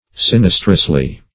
Sinistrously \Sin"is*trous*ly\ (s[i^]n"[i^]s*tr[u^]s*l[y^]),
sinistrously.mp3